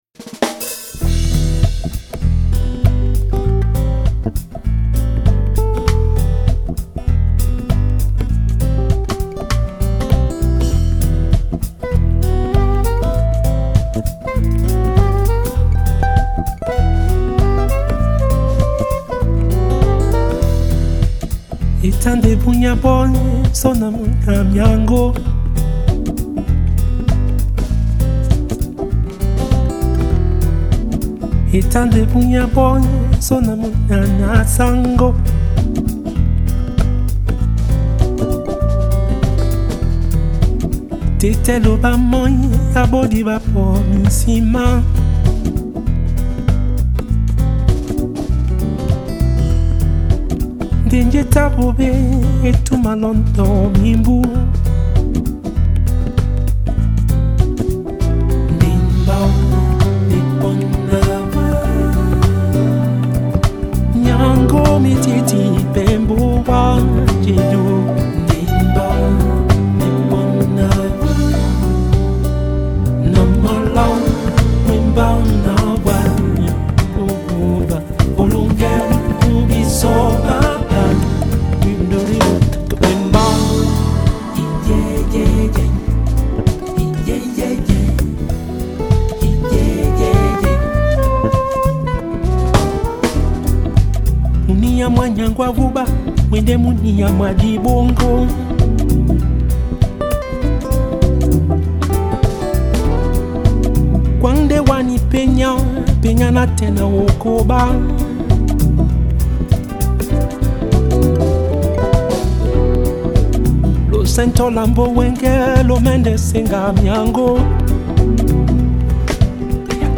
Melodic, light jazz